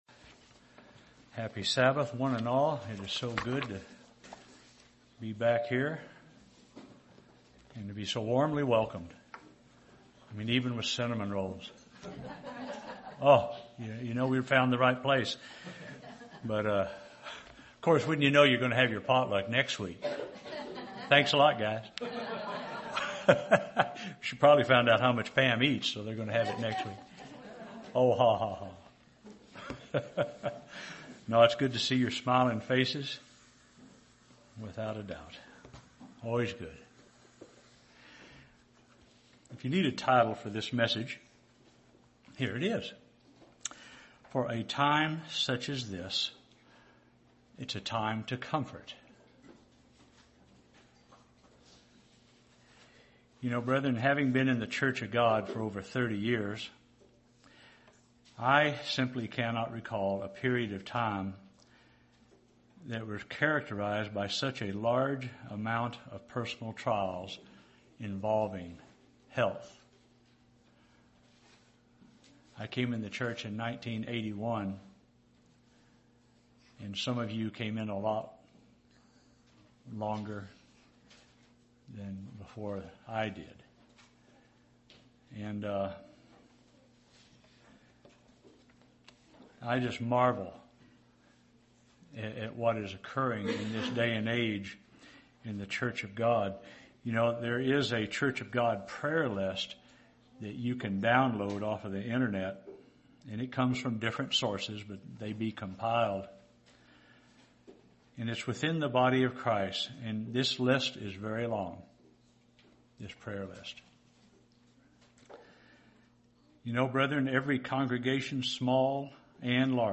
A sermon on comforting those who are in need of comfort.